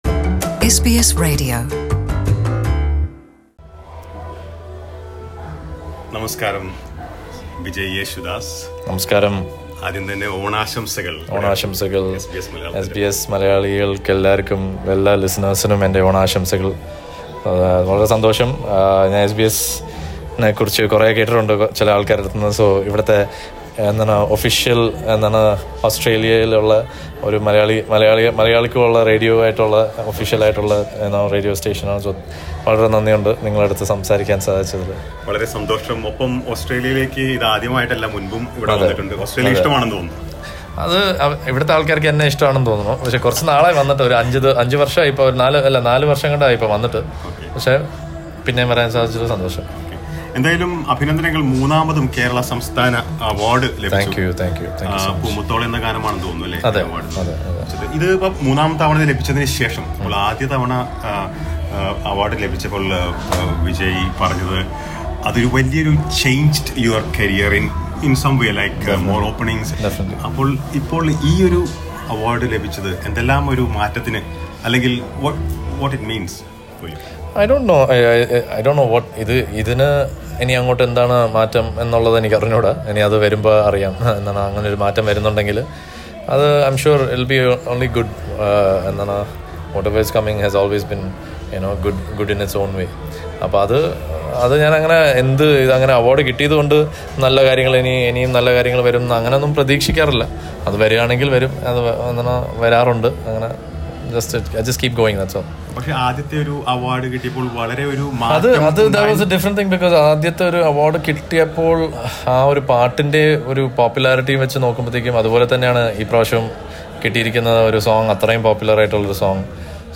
Malayalam playback singer Vijay Yesudas who was the Chief Guest at the Onam celebrations organised by Melbourne Malayalee Federation speaks to SBS Malayalam. Listen to the interview .